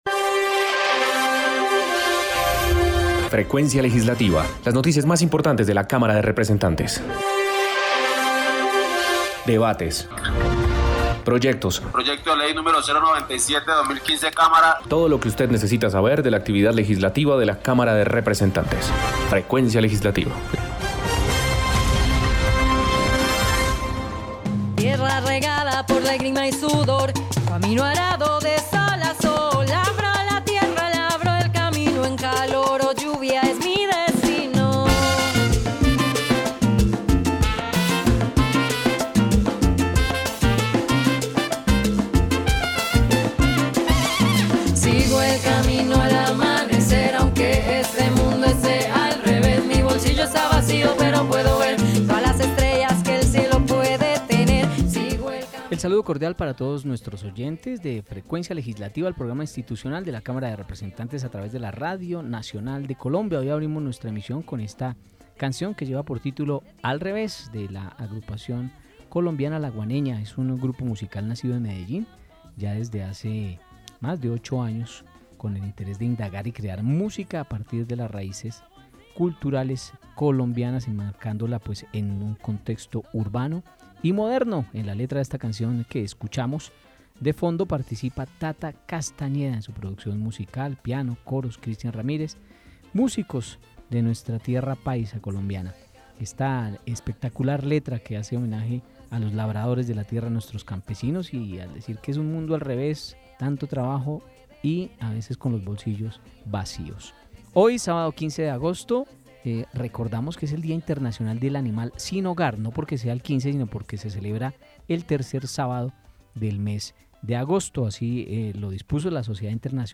Programa Radial Frecuencia Legislativa. Sábado 15 de Agosto de 2020